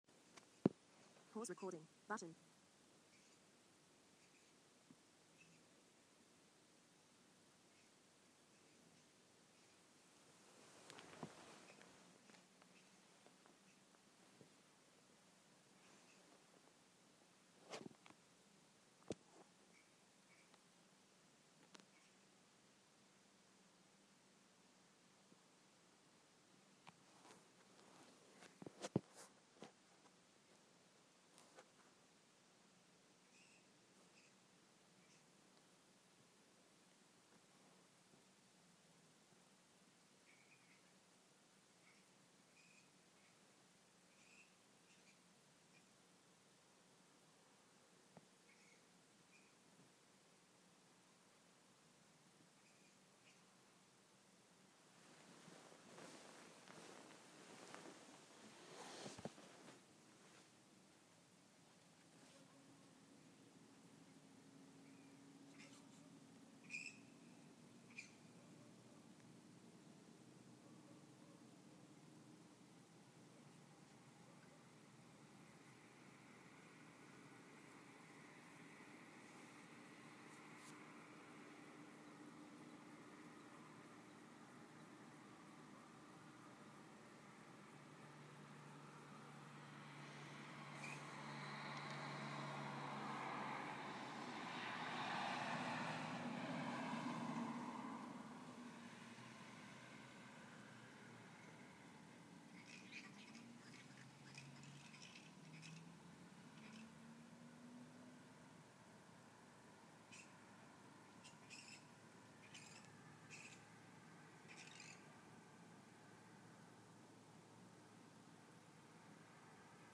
2 am flying-fox Corus
can't sleep, so decided to record a few minutes of the flying-foxes. A bit quiet to start with, but gets louder. In summer theres a lot more of them, and you can often hear them flapping around in the trees fighting.But this is pretty good for winter.